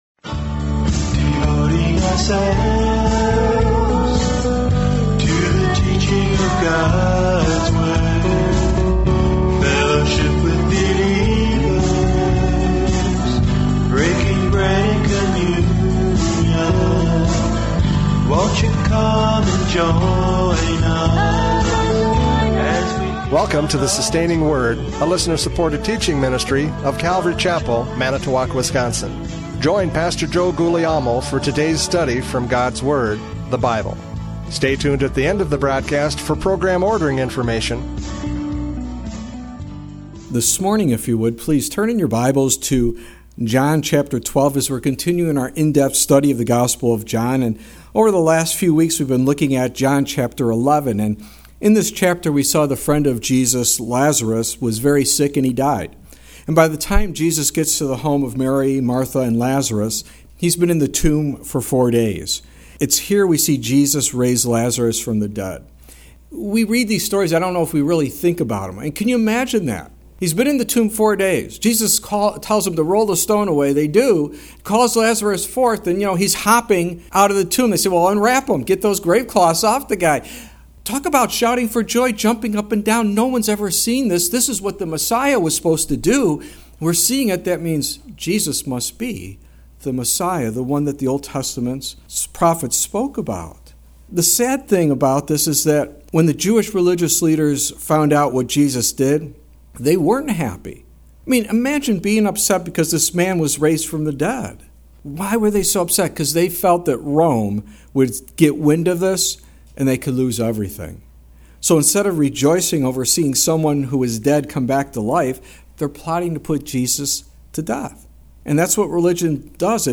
John 12:1-8 Service Type: Radio Programs « John 11:45-57 Blind Leaders!